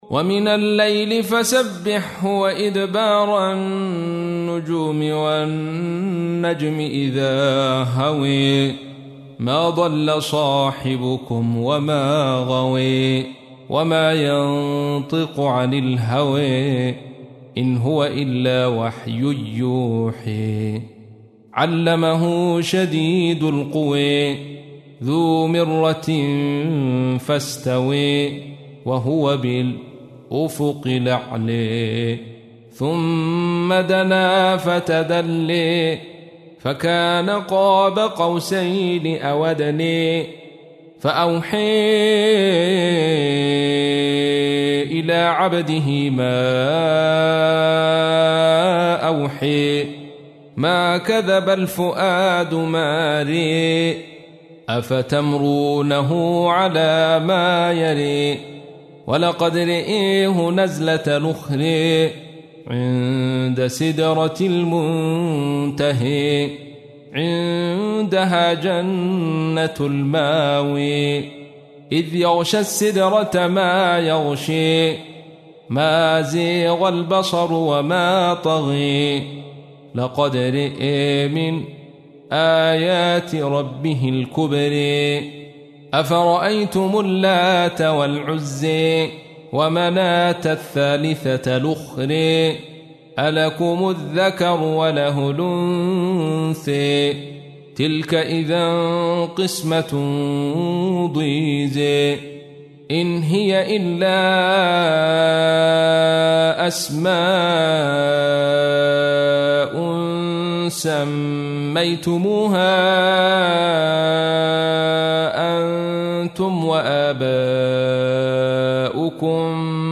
تحميل : 53. سورة النجم / القارئ عبد الرشيد صوفي / القرآن الكريم / موقع يا حسين